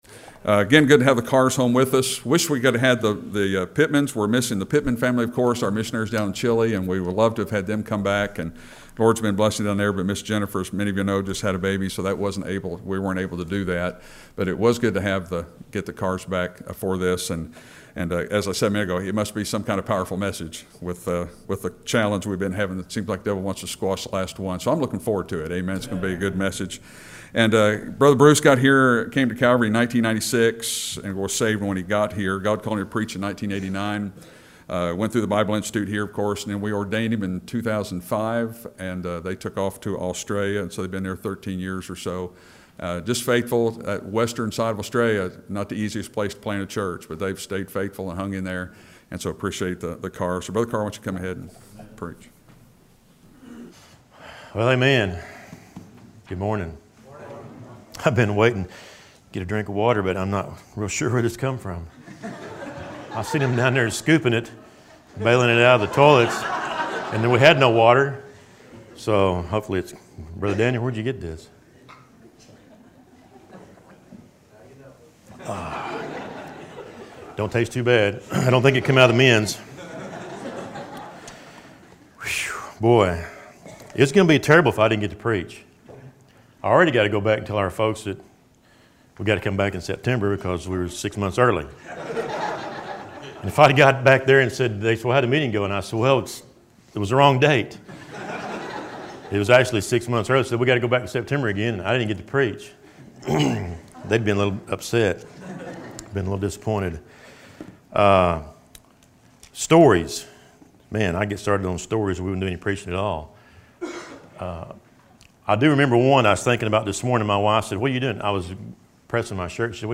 Sermons List | Calvary Baptist Church